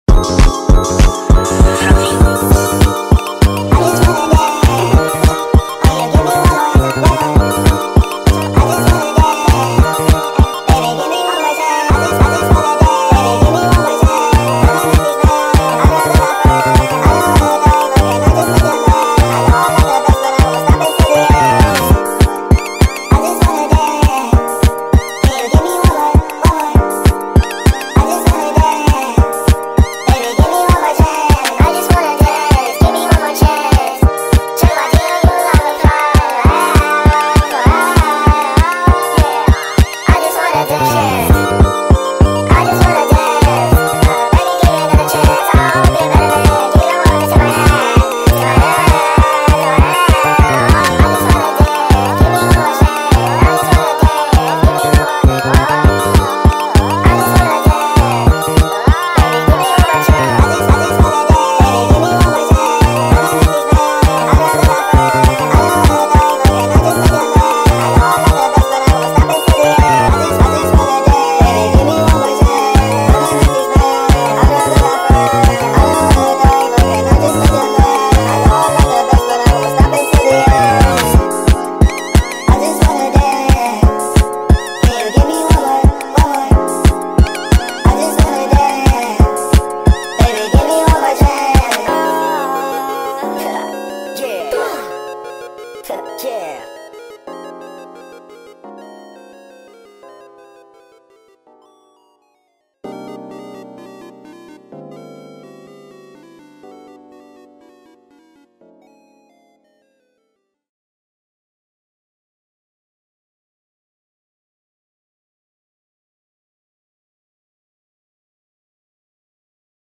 • Качество: 320 kbps, Stereo
sped up remix